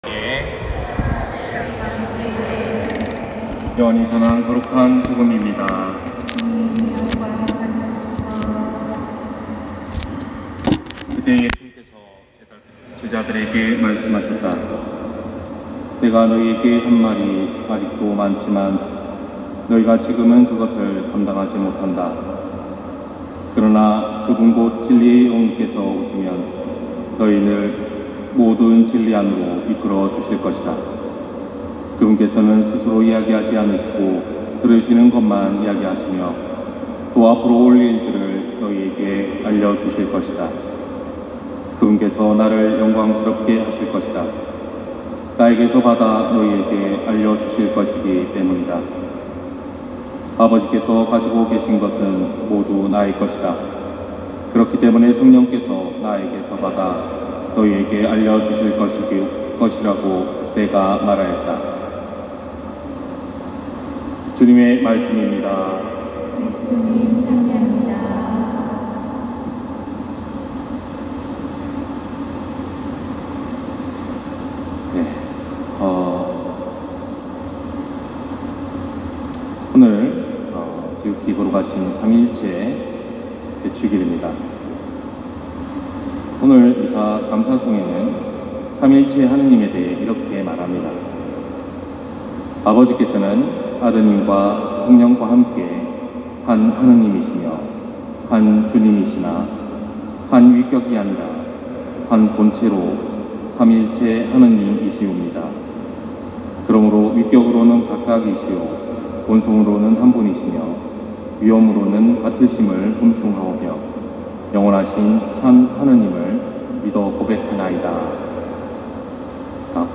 250614 신부님강론말씀